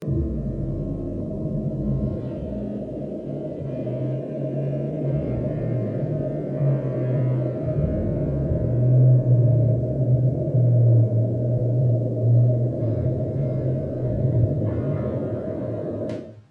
Shapeshifting Sub Layer
Shapeshifting Sub Layer.mp3